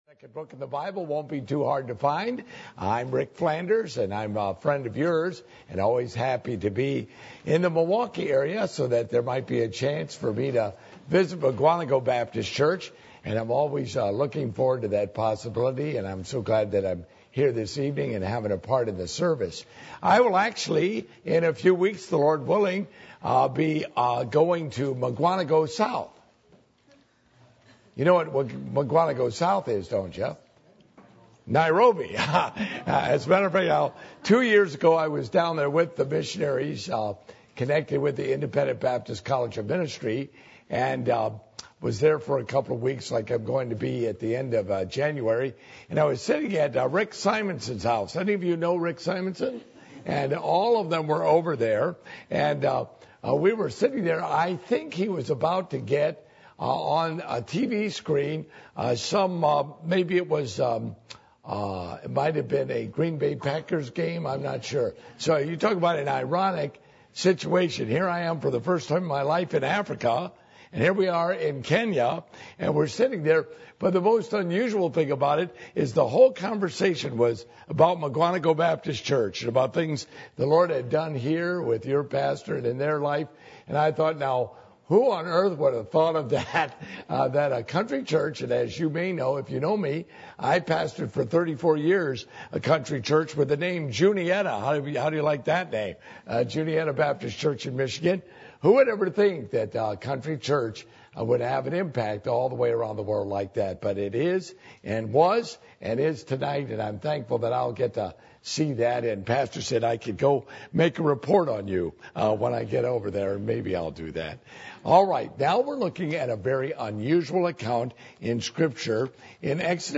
Passage: Exodus 34:5-9 Service Type: Midweek Meeting %todo_render% « The Keys To Being A Witness For The Lord Spirit Baptism